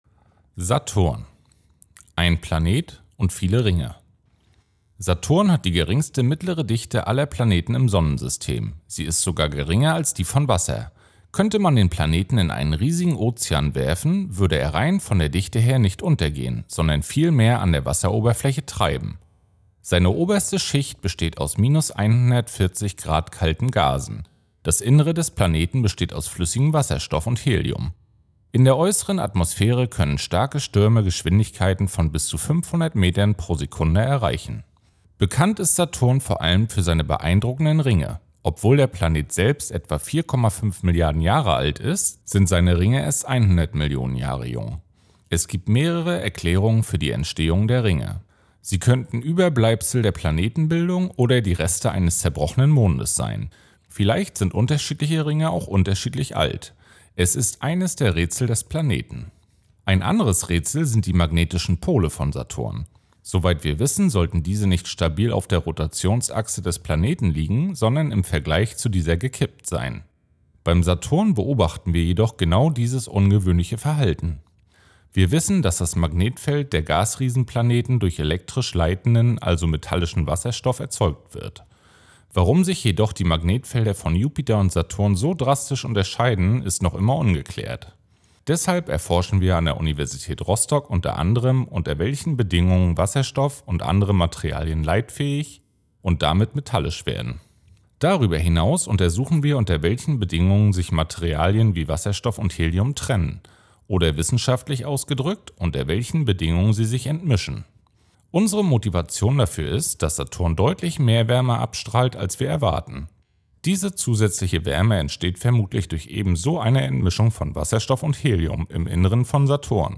Audioguide
Promovierende vom Institut für Physik der Uni Rostock geben spannende Einblicke in ihre Forschung und erklären das Weltall.